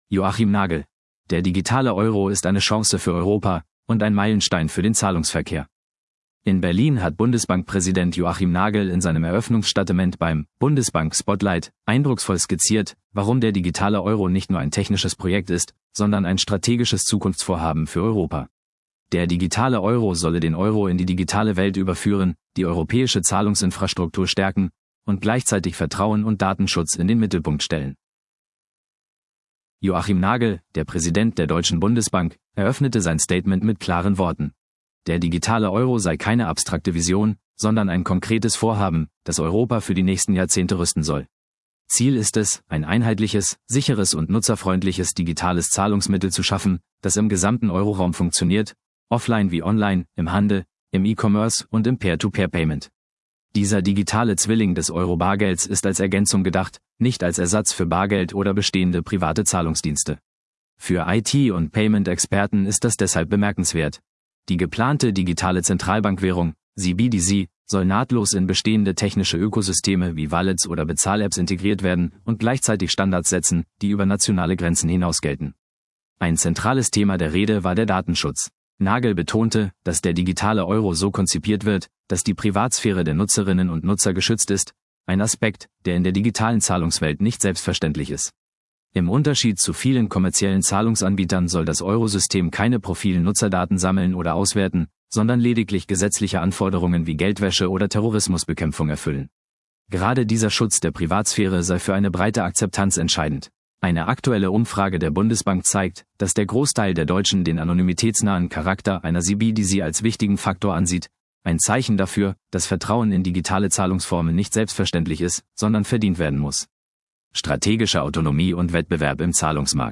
In Berlin hat Bundesbankpräsident Joachim Nagel in seinem Eröffnungsstatement beim „Bundesbank Spotlight“ eindrucksvoll skizziert, warum der digitale Euro nicht nur ein technisches Projekt ist, sondern ein strategisches Zukunftsvorhaben für Europa.